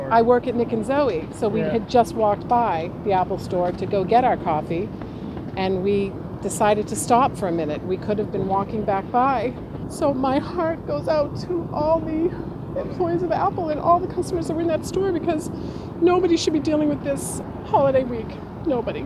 spoke with reporters at the scene